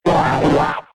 Cri de Psykokwak K.O. dans Pokémon X et Y.